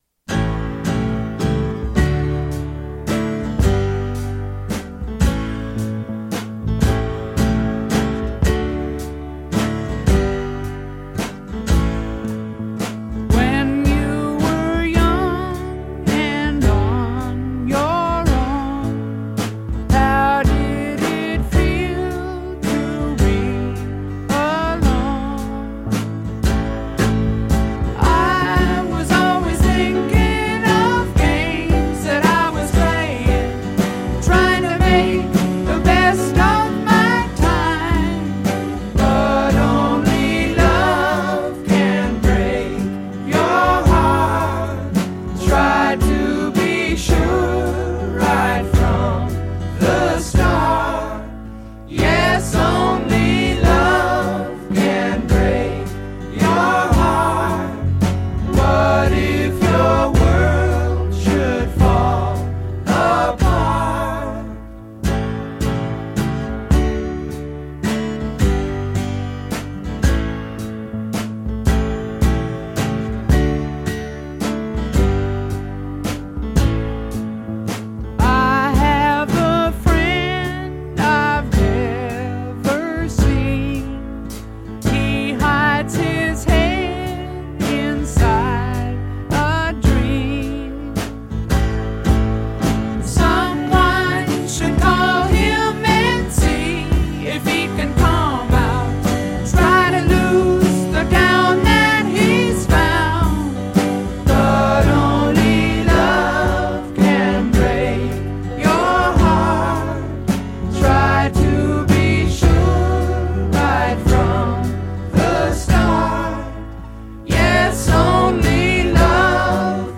موسیقی فولک موسیقی فولک راک